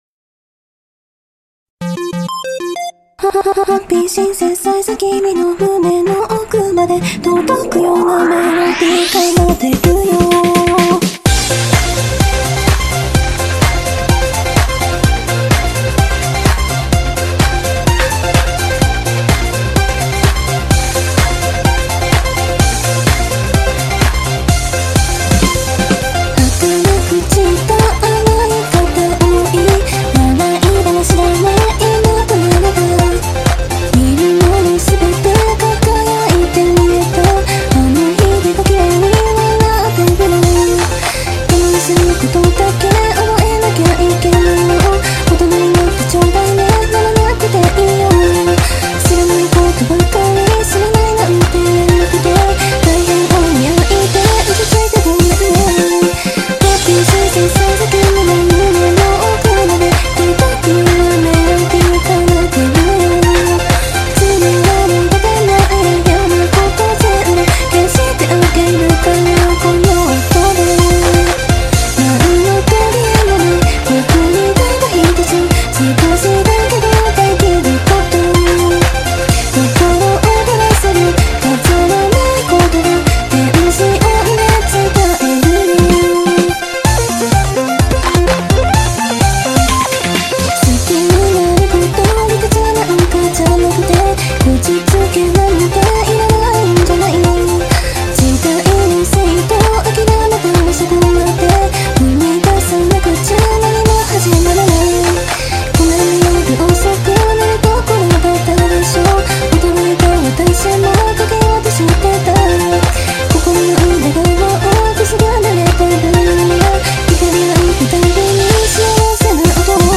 久しぶりに歌いたくなって歌ってしまいました、、お付き合いくださる方は追記からどうぞ！(音量注意)